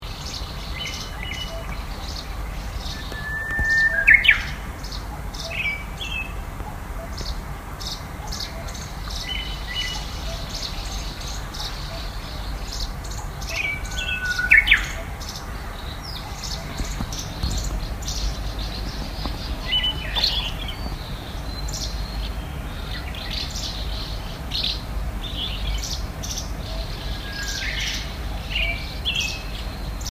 翌朝は綺麗な小鳥のさえずり*で起床。すばらしく美しい声に聞き惚れる。
*小鳥のさえずり